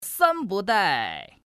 Index of /qixiGame/test/guanDan/goldGame_bak/assets/res/zhuandan/sound/woman/